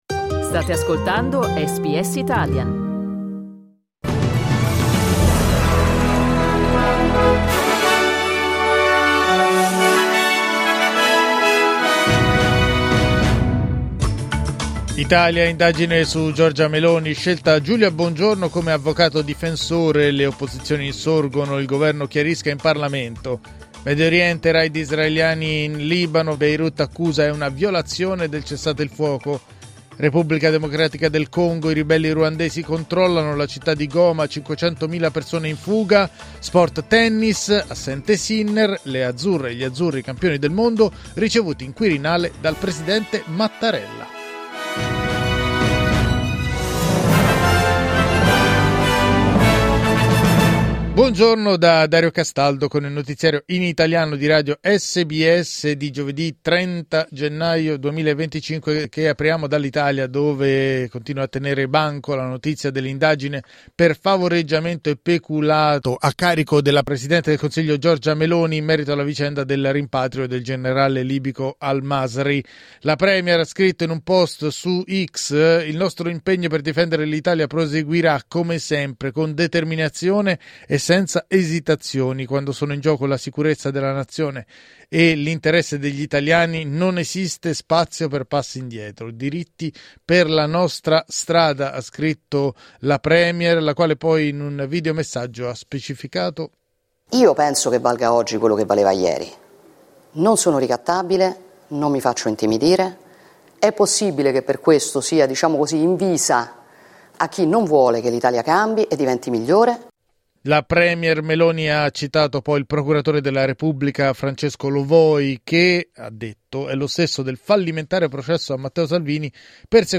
Giornale radio giovedì 30 gennaio 2025
Il notiziario di SBS in italiano.